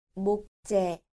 • 목재
• mokjae